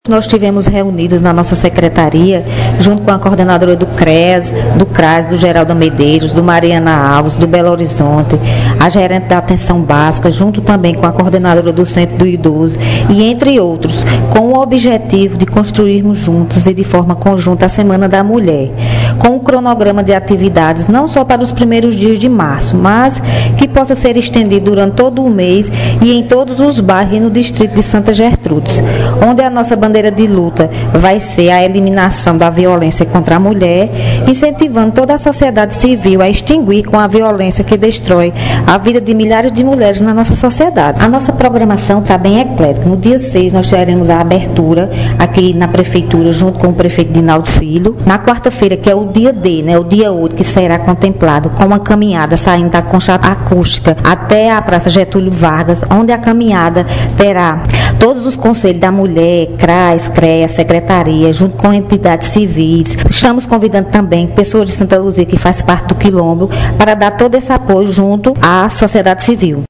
Fala da secretária da Mulher, Samira Medeiros, sobre a Semana da Mulher –